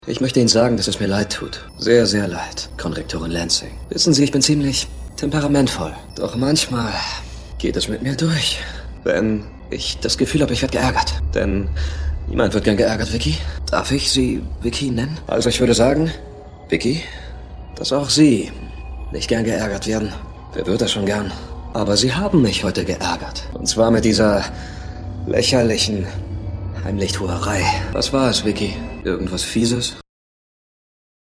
Synchron/Dubbing, Werbung, Games, HĂ¶rspiel, Off-Voice, Voice-Over, Sonorig, Fein, Klar, Sonorig, Warm, Markant, Entspannt, Energetisch, Dominant, Charmant, Jugendlich, Frisch, LĂ€ssig, Cool, MĂ€nnlich, Markant, VerfĂŒhrerisch, Jung, Hip, Provokativ, Herausfordernd, DĂŒster, BĂ¶se, Verrucht, Emotional, Weich, Sensibel, Lustig, SchrĂ€g, Komisch, Understatement, Trickstimme, Native Speaker (Griechisch, Deutsch), Englisch (AE/BE), Tenor, Verspielt, Charge
Sprechprobe: Sonstiges (Muttersprache):
Actor, Dubbing, Advertisement, Games, Audio Drama, Voice-Over, Native Speaker (Greek/German), English (US/UK), Warm, Masculin, Sensitive, Clear, Laid-Back, Cool, Young, Fresh, Hip, Charming, Seductive, Provoking, Challenging, Dark, Mad, Angry, Wicked, Emotional, Understatement, Comical, Funny, Comic-Voice, Playful, Tenor